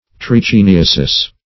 Trichiniasis \Trich`i*ni"a*sis\, n. [NL.] (Med.)